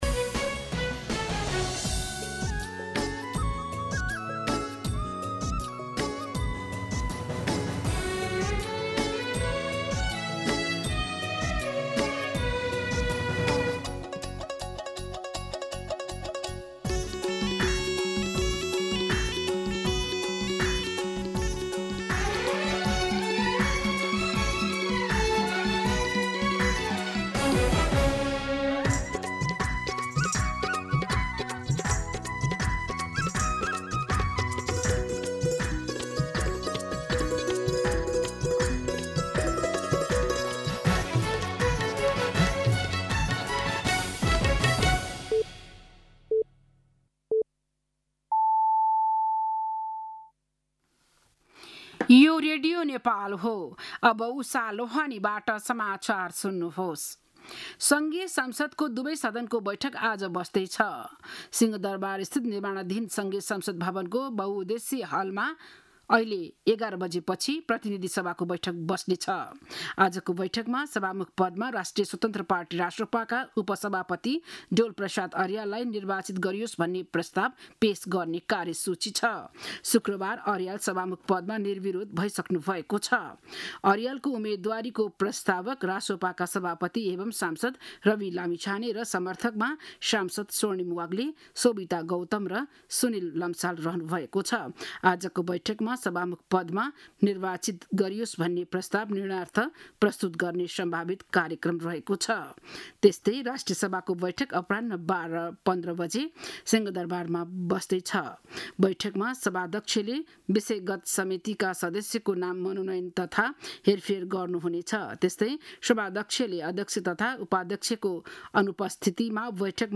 बिहान ११ बजेको नेपाली समाचार : २२ चैत , २०८२